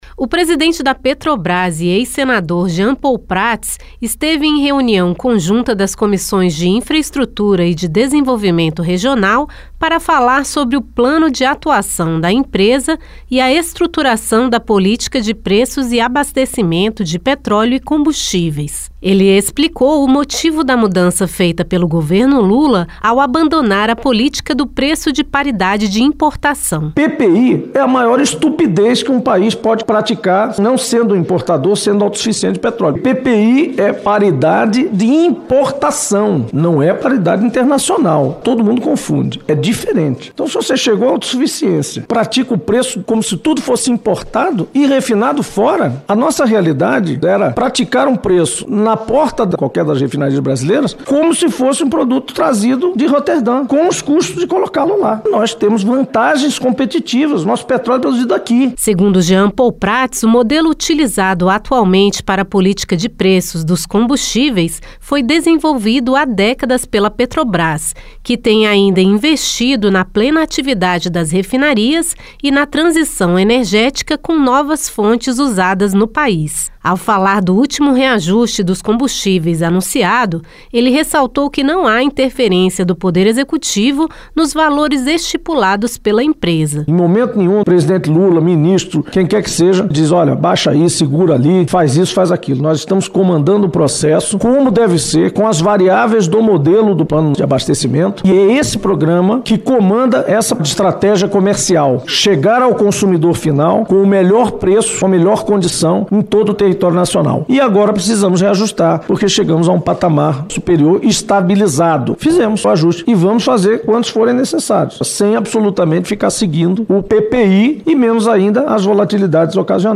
O presidente da Petrobras, Jean Paul Prates, explicou nesta quarta-feira (16) que reajustes de combustíveis não são mais feitos conforme critérios de importação, como no governo anterior, já que a maior parte do petróleo utilizado no país é nacional. Em audiência pública das Comissões de Infraestrutura (CI) e de Desenvolvimento Regional (CDR), Jean Paul, que é ex-senador, ressaltou a plena atividade das refinarias, o investimento em novas fontes energéticas e o plano da Petrobras de abastecimento para garantir preços estáveis à população.